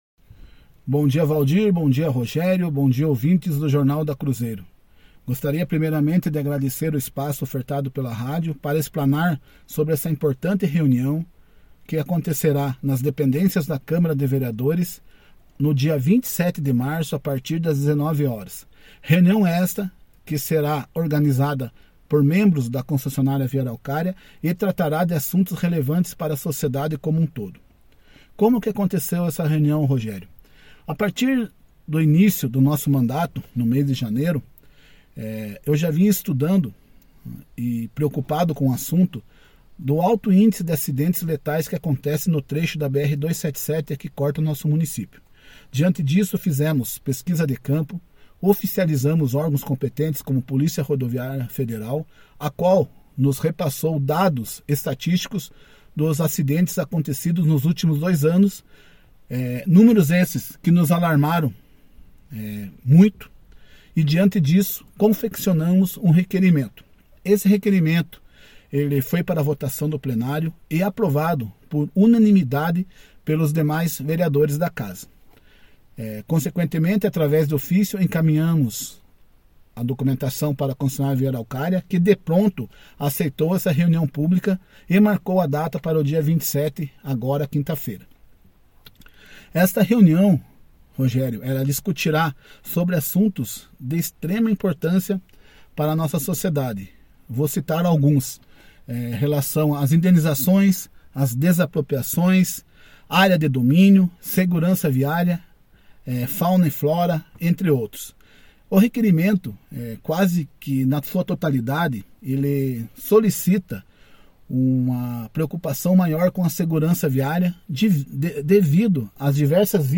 Autor do requerimento que solicitou a realização da reunião pública, o vereador Sargento Gaio (Republicanos), conversou com o jornalismo da Cruzeiro, falando sobre como foi organizada e como será realizada a reunião.